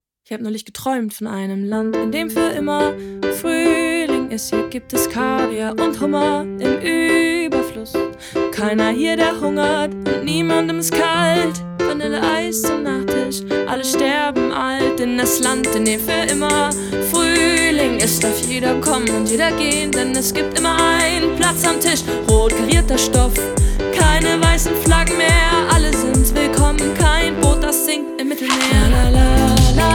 Жанр: Поп / Инди